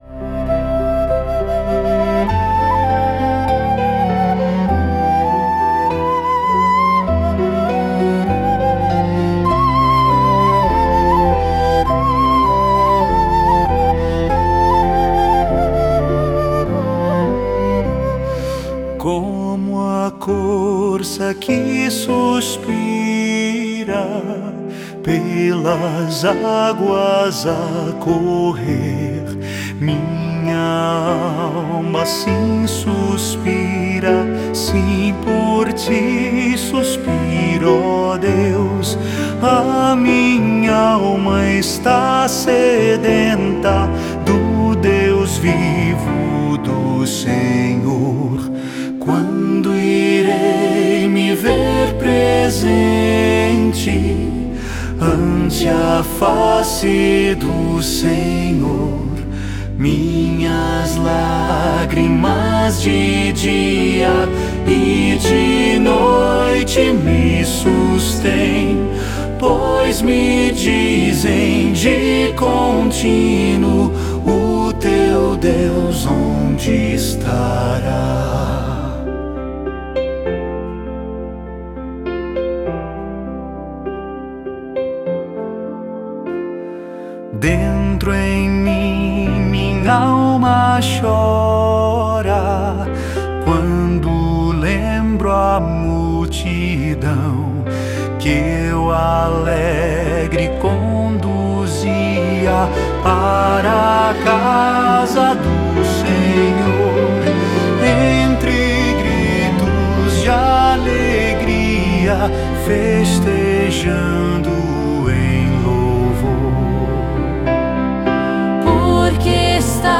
salmo_42B_cantado.mp3